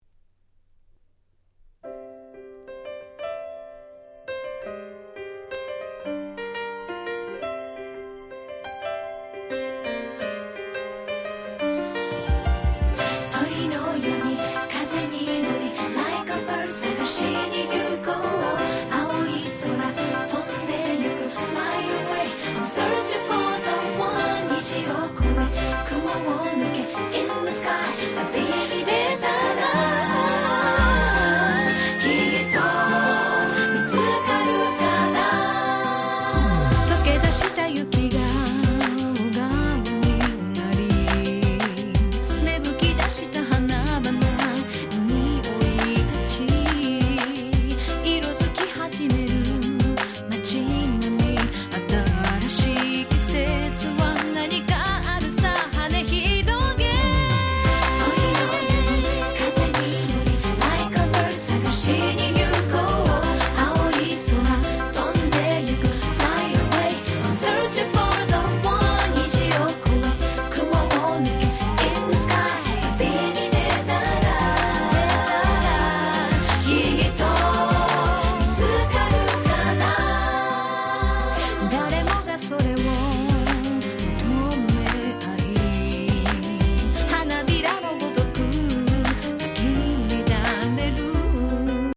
07' Nice Japanese R&B !!
彼女の魅力溢れる美しいMid R&B。